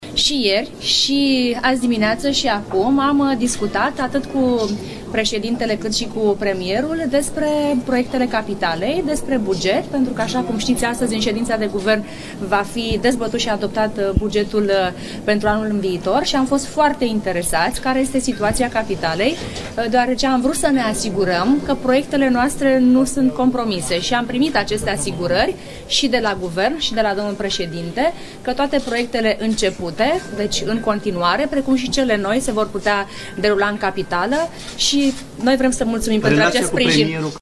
VOCE-Firea-despre-buget.mp3